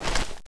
zoom_in.wav